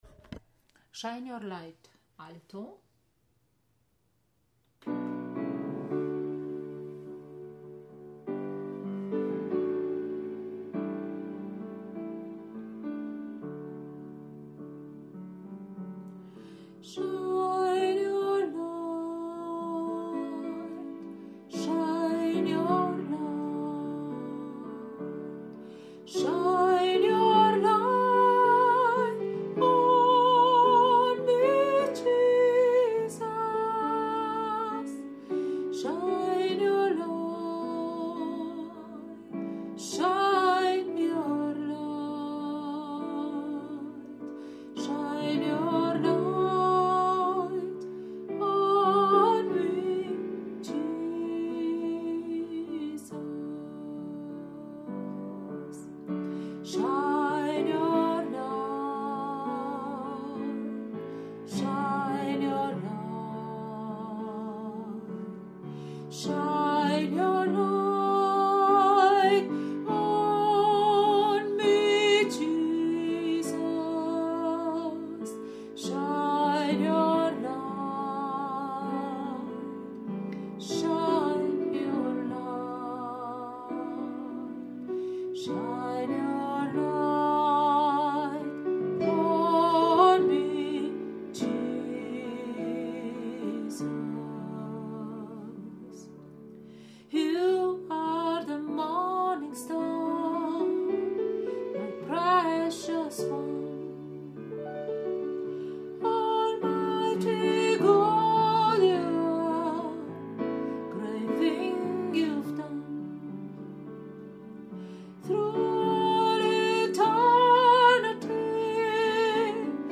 Shine your light Alto